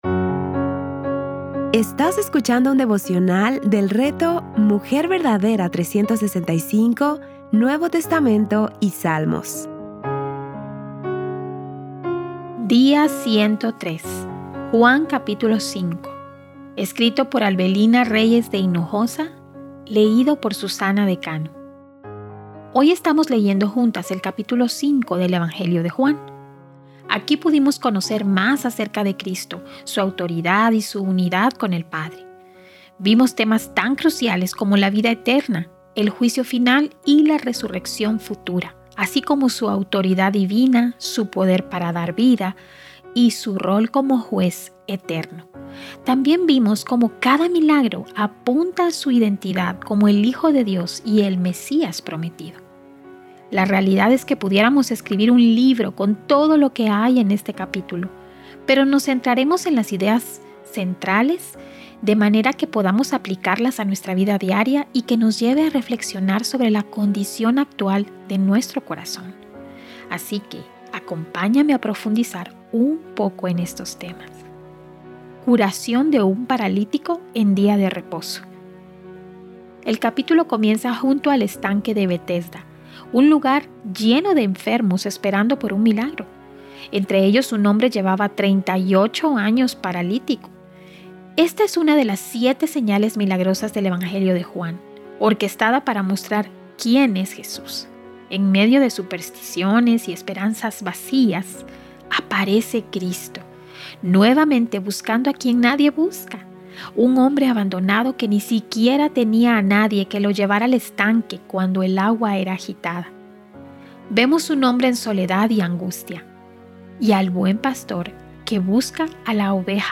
Series:  Juan y Salmos | Temas: Lectura Bíblica